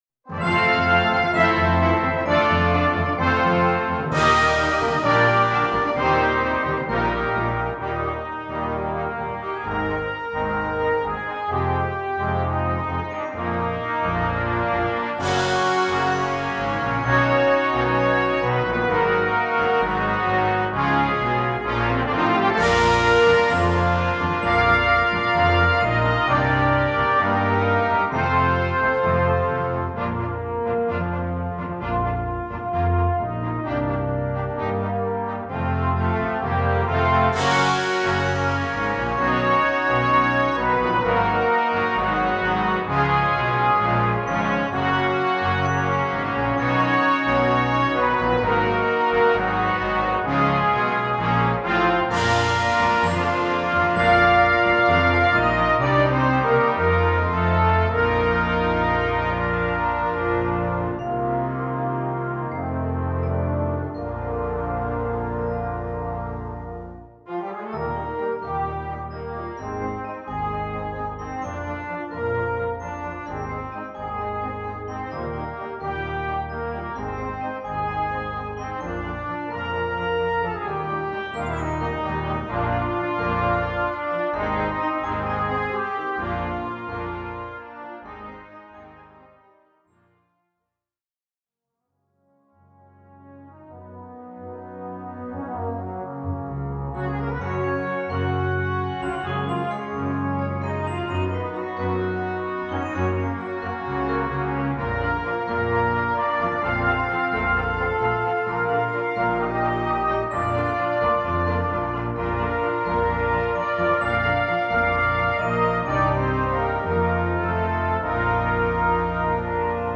Gattung: Filmmusik für Blasorchester
Besetzung: Blasorchester